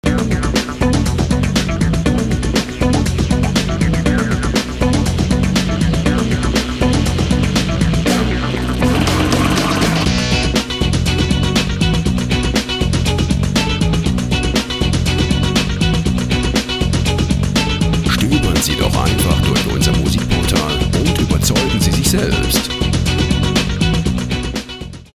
freie Musikbetts für Ihre Radiosendung
Musikstil: Funk
Tempo: 120 bpm